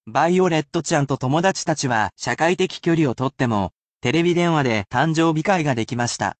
I read these aloud for you, as well, but you can use this as a useful opportunity to practise your reading skills.